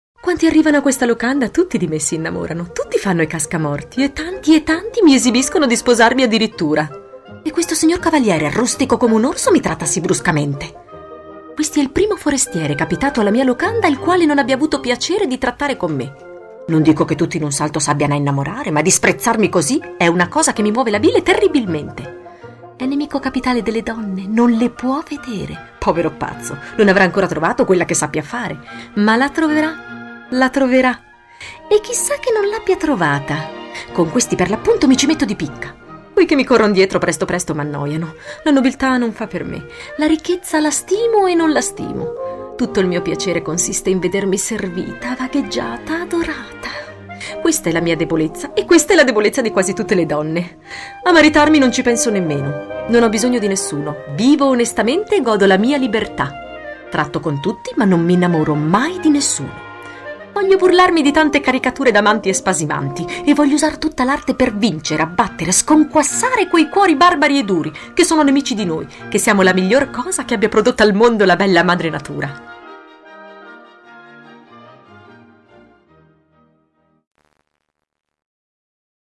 Sprecherin italienisch.
Sprechprobe: eLearning (Muttersprache):
female italian voice over talent.